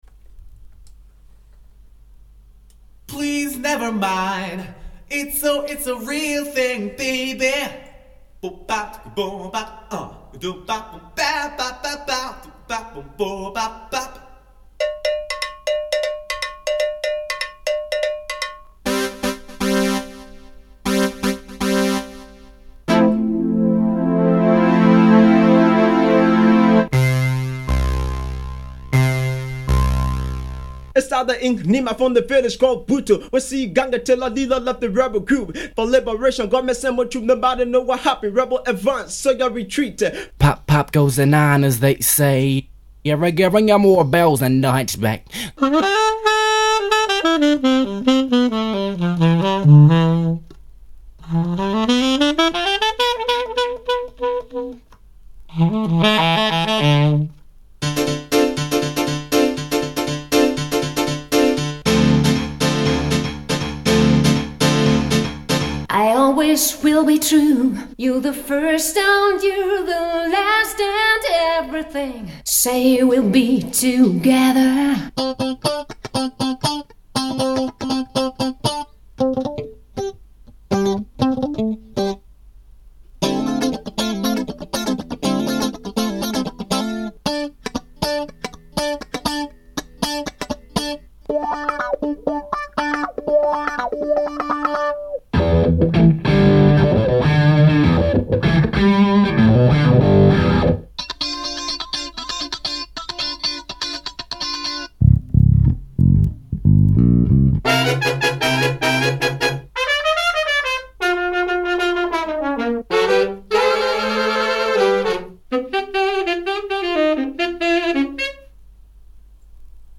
S/Nが悪いが気にしないでほしい．これはそういうテストだから．
そこでフリー素材集からランダムに選んだ波形をリアンプしてAT4050で拾ってみた．
ボーカルのリバーブが妙な感じだが，掛け録りの素材だったから仕方ない．
素材波形をDM-24でモノラル化して6Dから出してマイクで拾った．
ただ環境音がやたらと入ってしまったので，これはよくなかった．